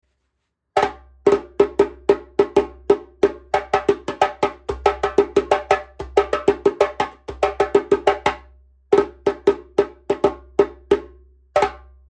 イロコ材の魅力の重すぎず軽快＆カジュアル、音のバランスの良さ。